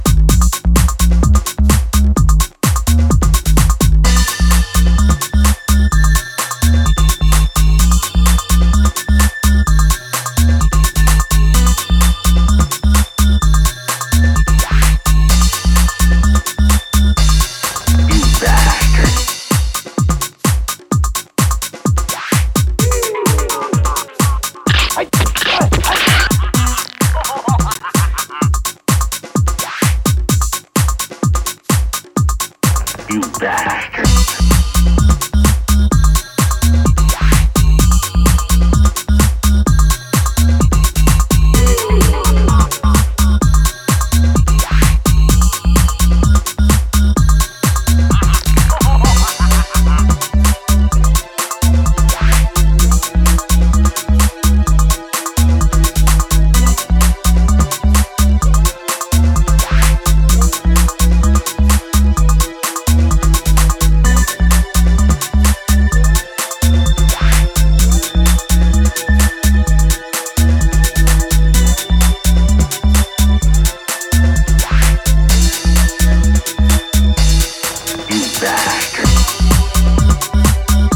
the e.p is full of timeless, heavy, dancefloor rhythms.